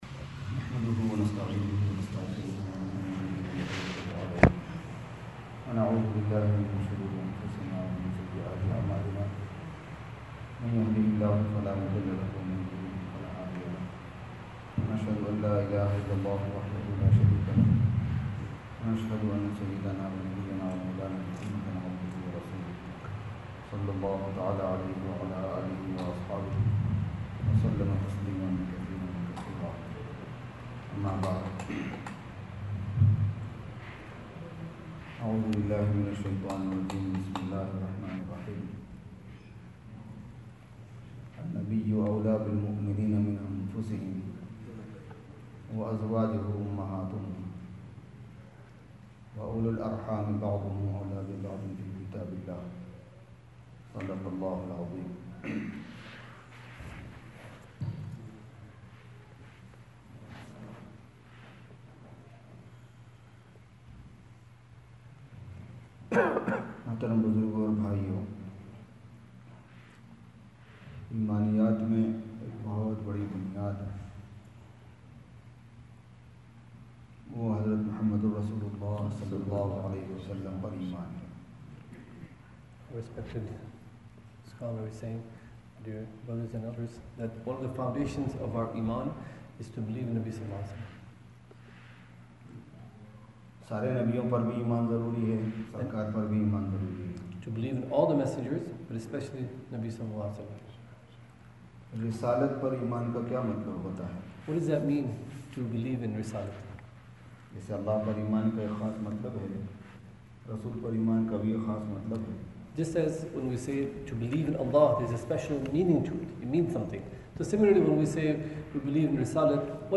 Masjid AlHuda Bayan.mp3